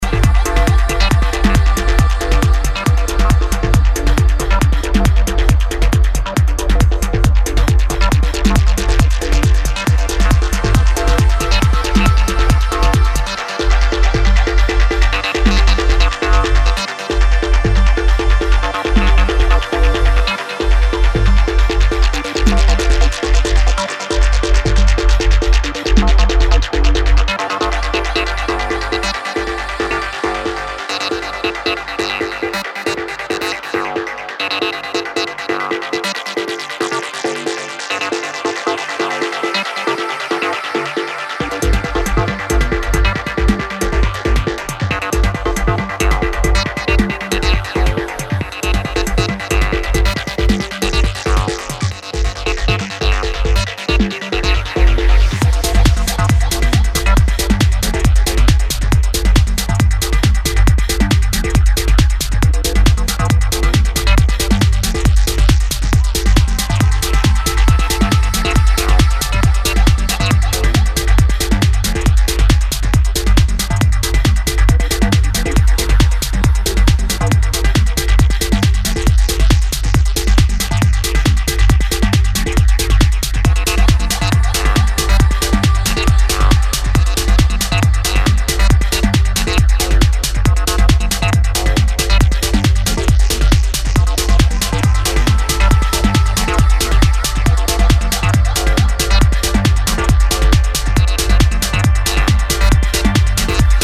driving techno